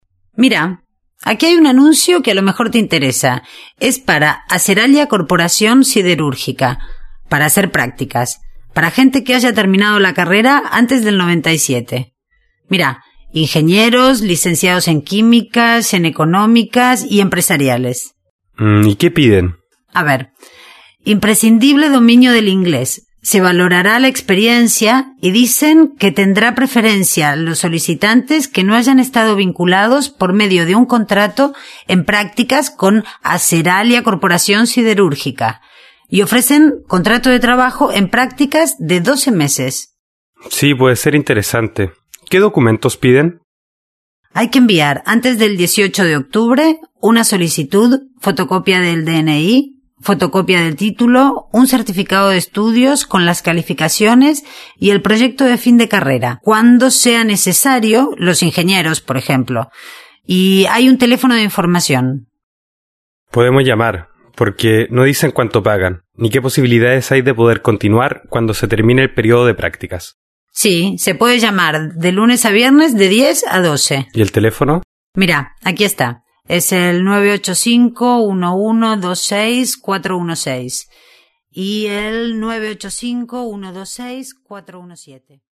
2. Escuche otra vez el diálogo y decida si las siguientes frases son verdaderas o falsas.